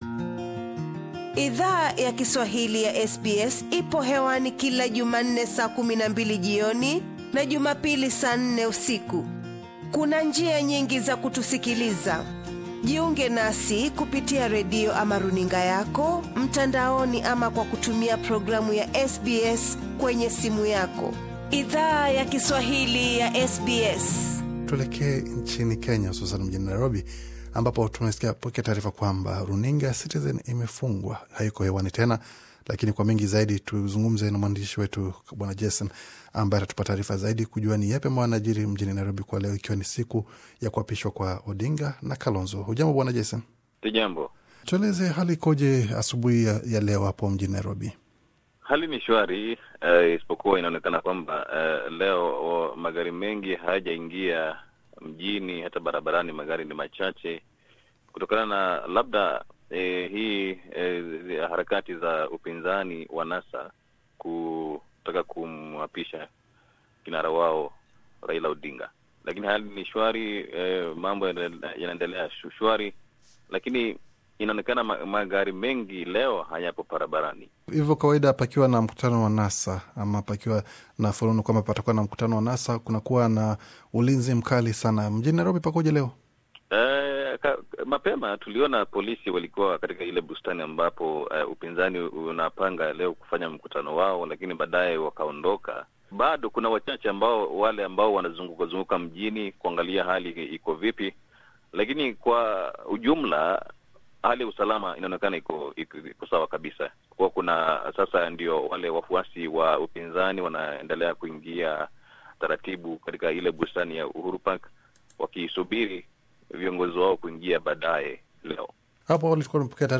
Hatua hiyo ime jiri wakati muungano wa NASA ulikuwa ukiendelea na maandalizi ya kuwaapisha vinara wao katika bustani ya Uhuru. SBS Swahili ilizungumza na mwandishi wa habari akiwa mjini Nairobi kuhusu matukio hayo.